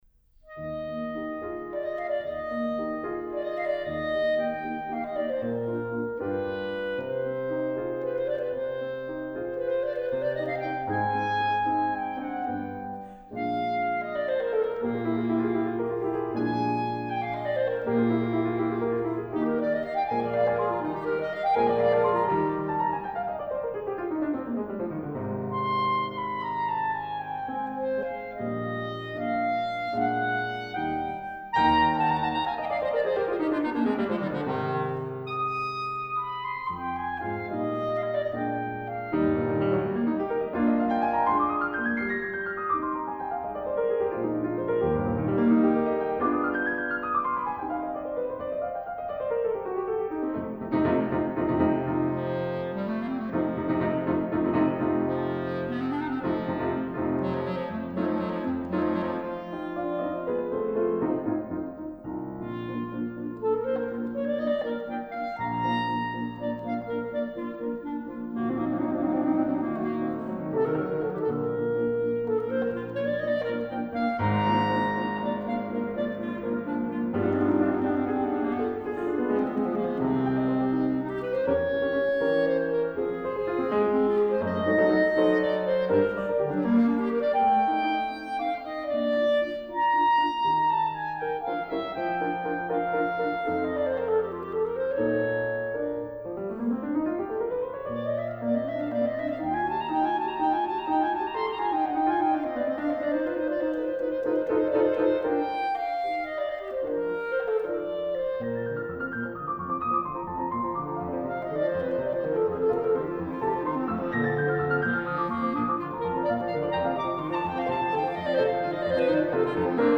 Clarinet
Piano 8:04 Debussy